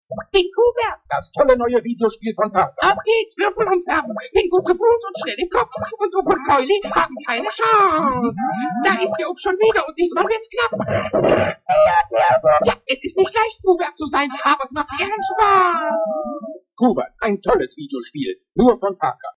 Q*bert (Parker) German Audio Commercial (Q-bert Qbert)
Great Q*bert audio commercial in German.
q-bert_commercial.mp3